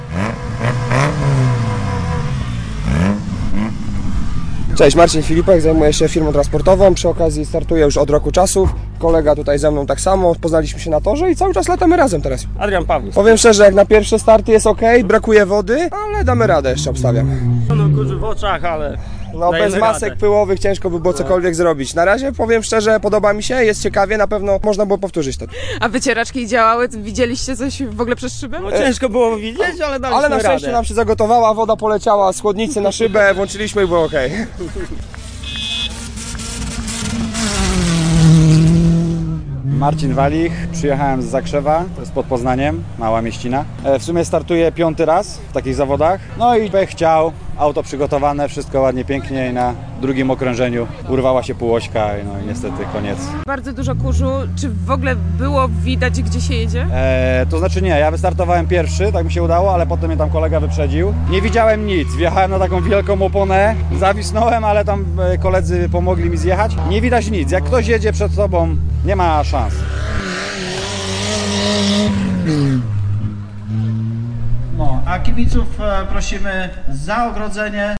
Kurz i ryk silników, czyli wyścig wraków
W Głogowie wystartował I Wyścig Wraków.
Na torze przy ulicy Łąkowej nie brakowało emocji, ryku silników i unoszącego się w powietrzu zapachu spalin.